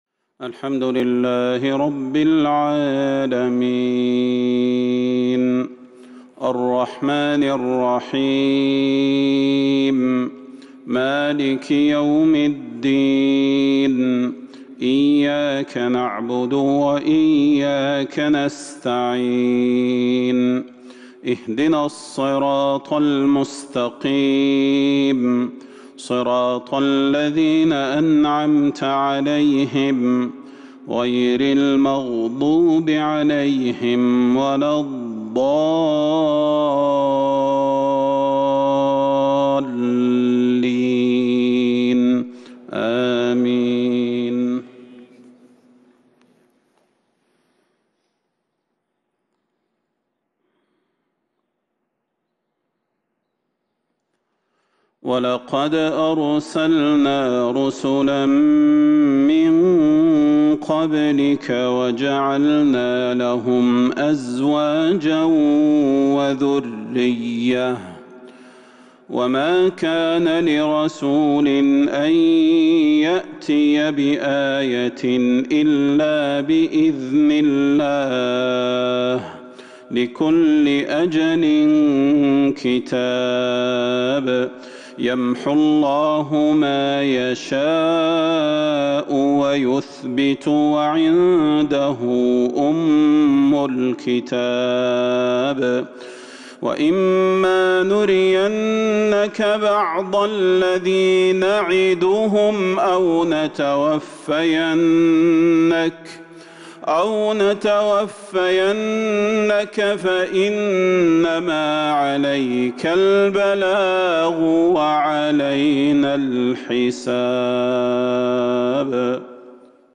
صلاة المغرب 9-6-1442هـ من سورة الرعد | maghrib Prayar from Surah Ar-raad ' 22/1/2020 > 1442 🕌 > الفروض - تلاوات الحرمين